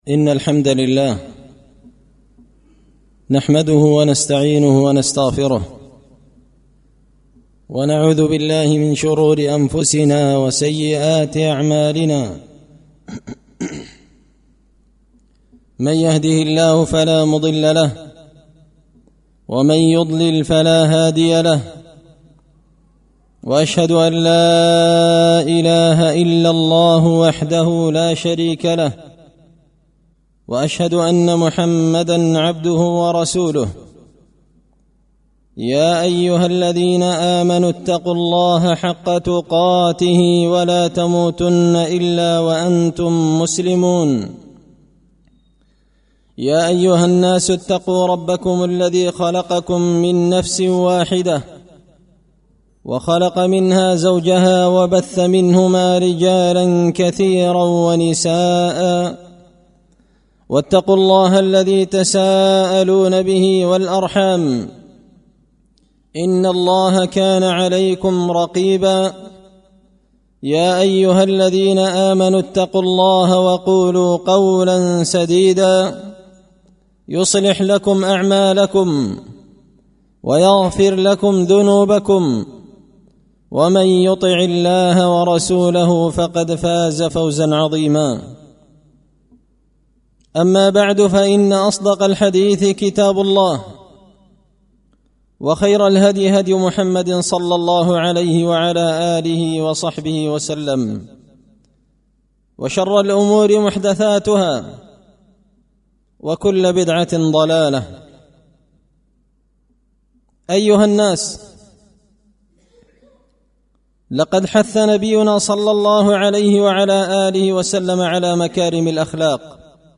خطبة جمعة بعنوان – مكارم الاخلاق
دار الحديث بمسجد الفرقان ـ قشن ـ المهرة ـ اليمن